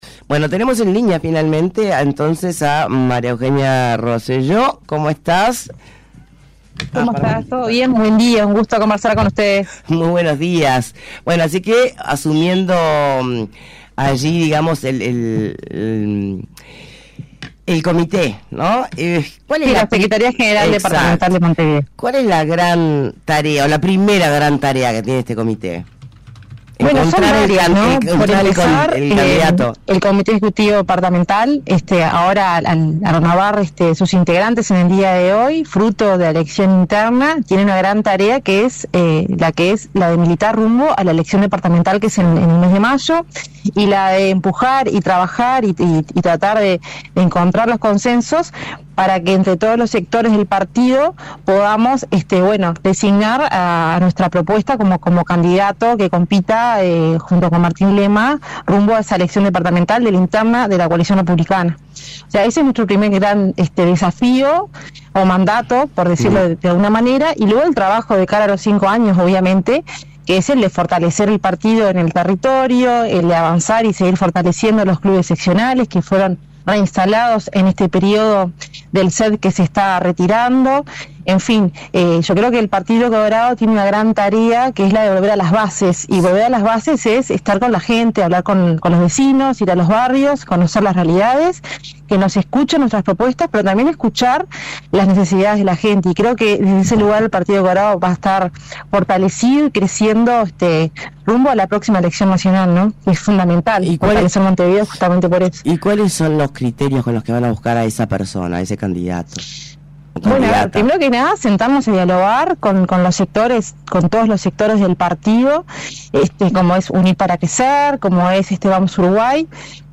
Entrevista a María Eugenia Roselló: